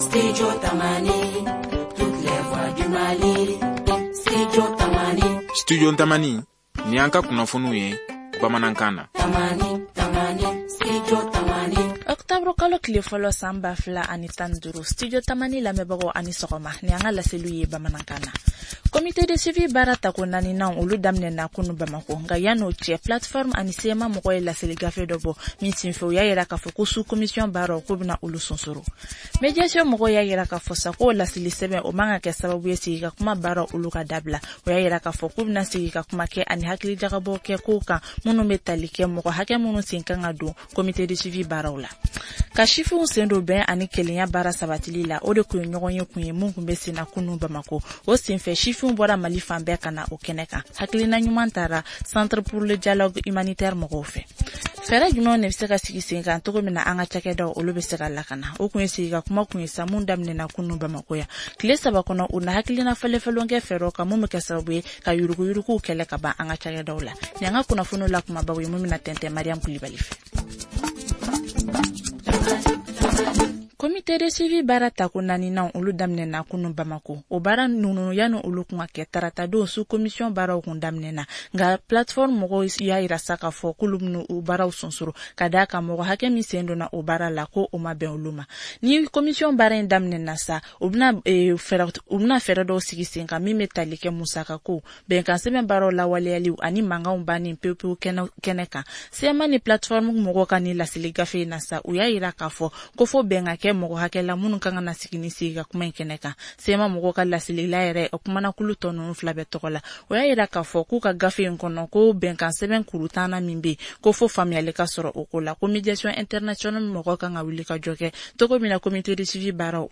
Journal en bambara: Télécharger